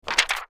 paper_pickup4.wav